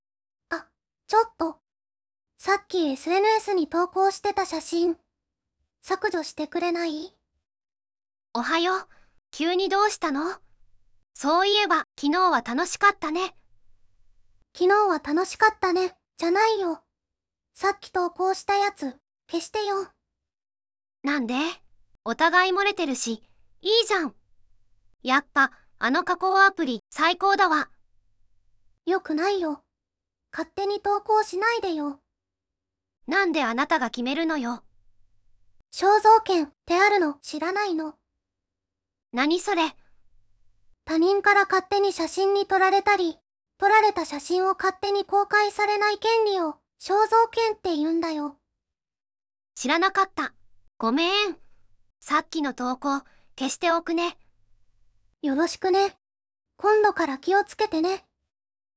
ネットトラブル音声ドラマ
よくあるネットのトラブルを、音声ドラマで再現しています。
※声は「VOICEVOX」サイトからの音声を使用させていただいてます。